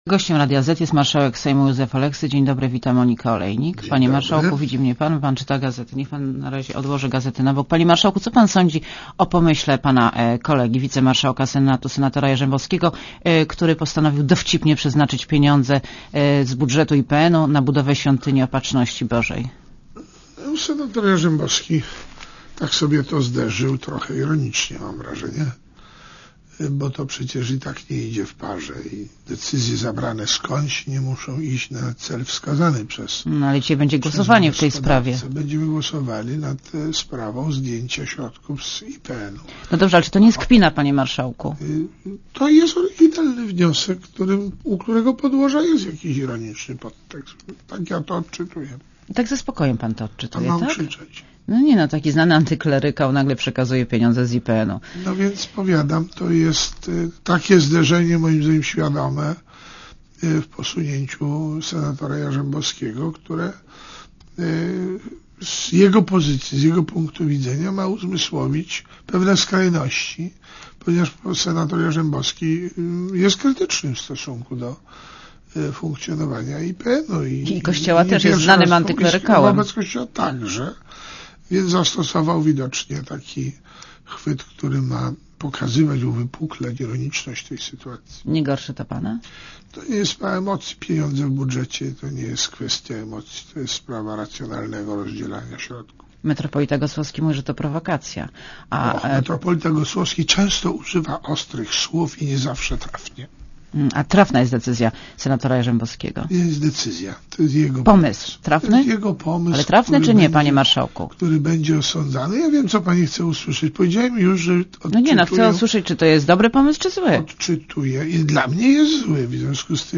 Gościem Radia Zet jest marszałek Sejmu, Józef Oleksy.